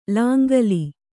♪ lāngali